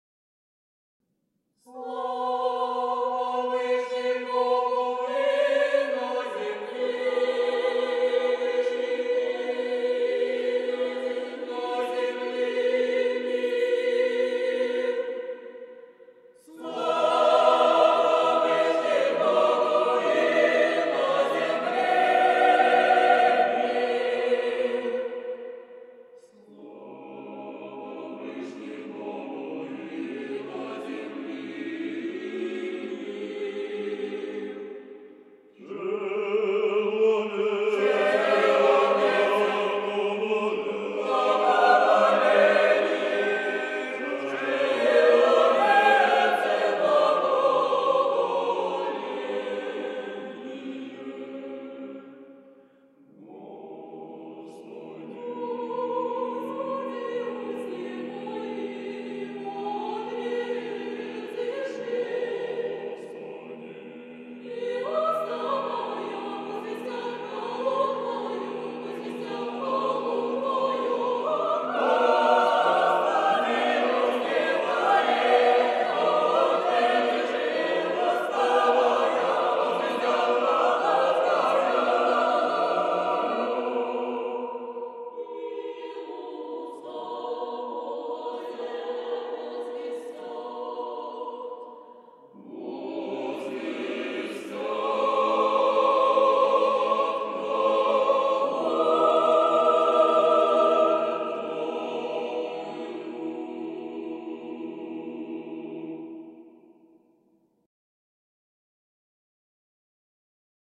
Православни песнопения